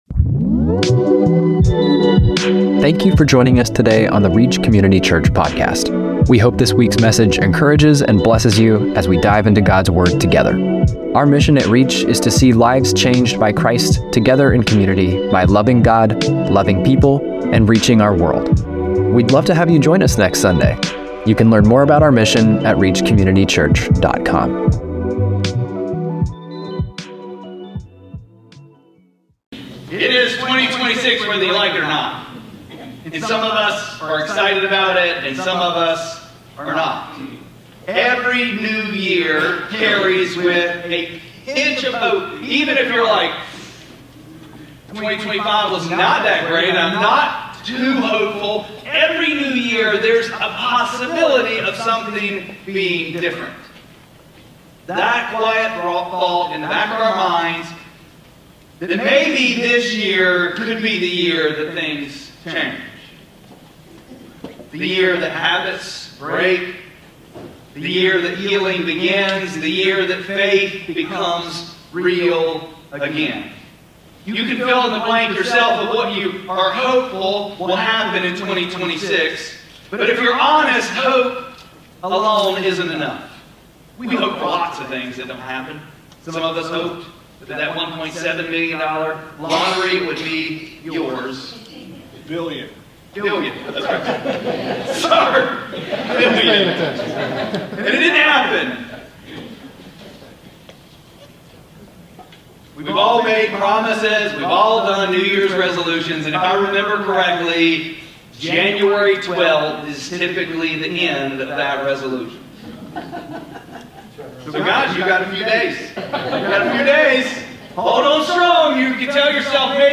1-4-26-Sermon.mp3